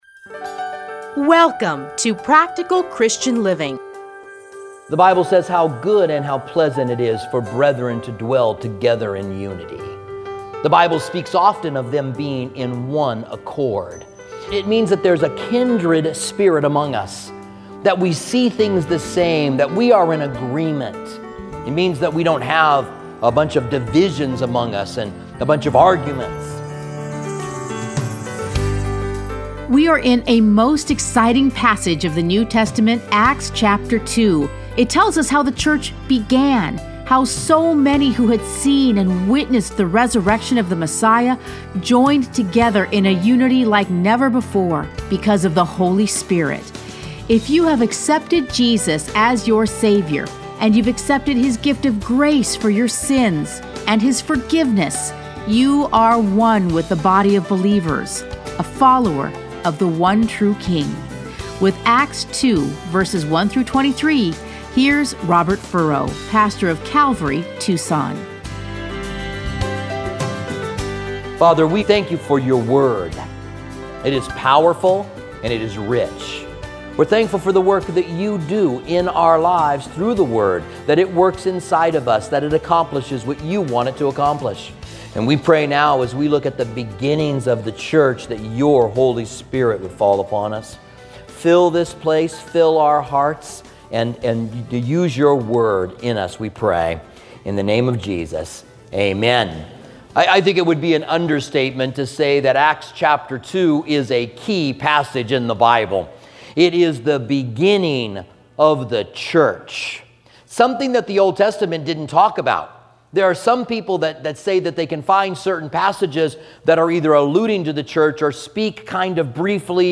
Listen to a teaching from Acts 2:1-23.